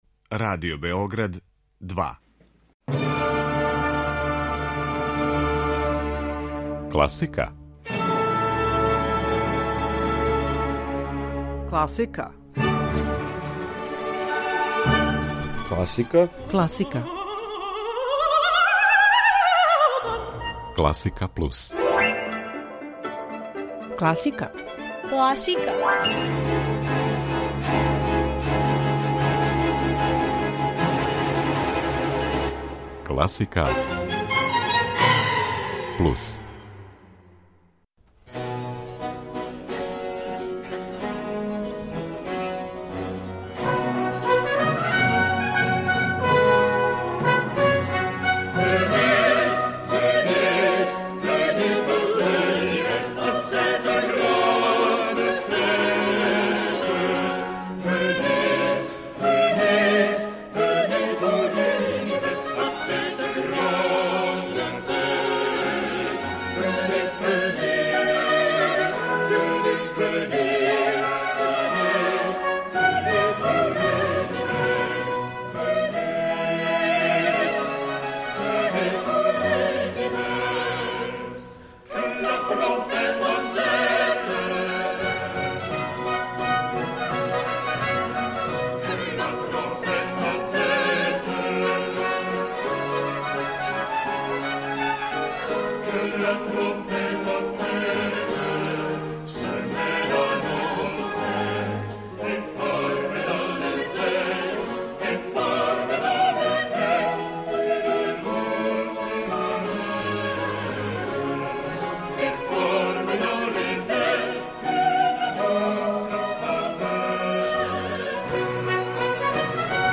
Емисија класичне музике
У рубрици „На други начин" представљамо интересантан спој грегоријанског напева и соло клавира.